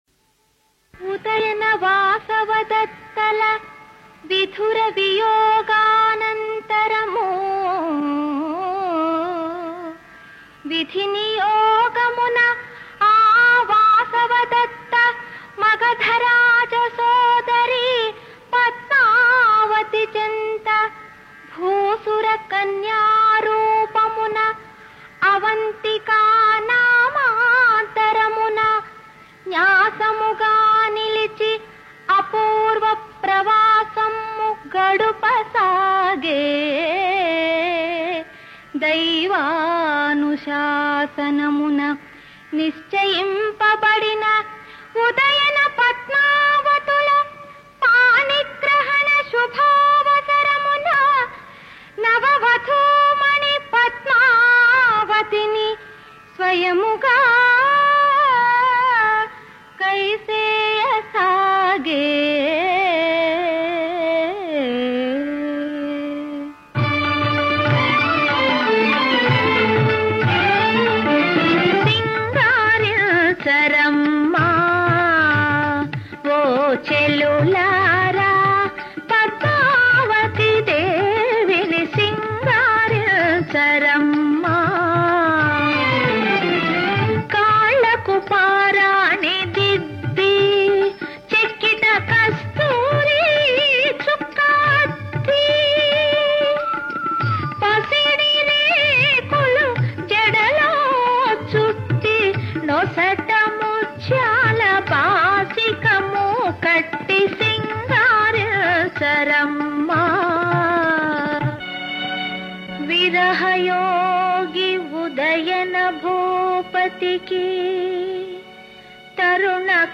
నృత్య నాటకము